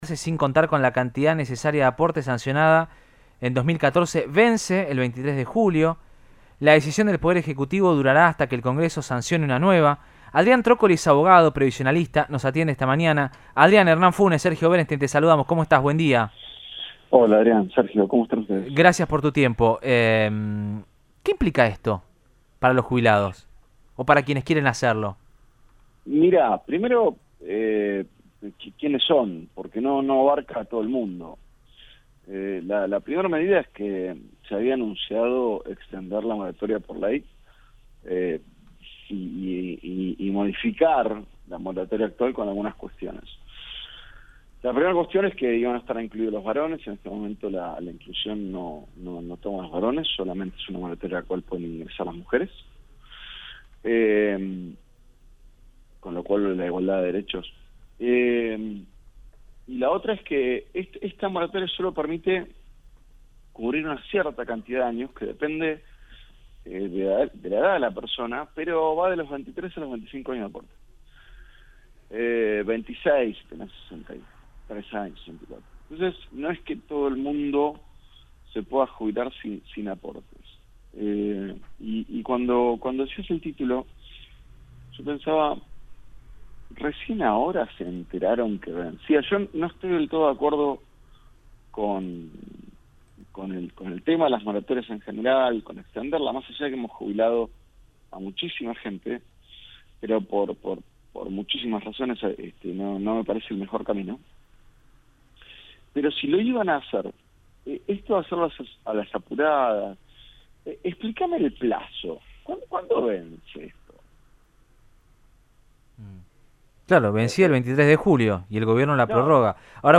Otra de las críticas que realiza el abogado previsionalista es la duración de la misma: “Esta prórroga se hace hasta que el Congreso decida y ¿si no decide nunca?”, se pregunta y dice “¿estará vigente para hacerlo?”.